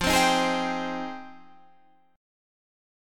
Fm9 chord